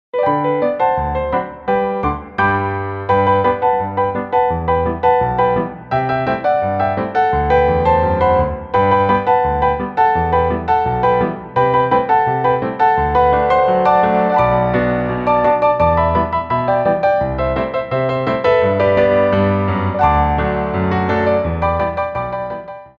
Petit Allegro 3
2/4 (8x8)